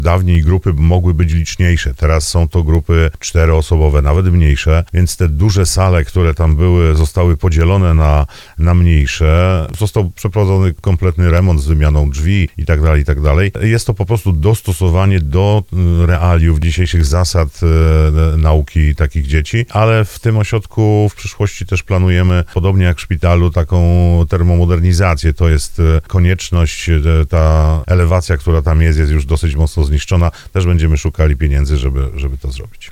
Jak mówił w rozmowie Słowo za Słowo starosta brzeski Andrzej Potępa, remont musiał zostać przeprowadzony, by dostosować ośrodek do nowych przepisów.